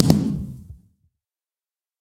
largeblast1.ogg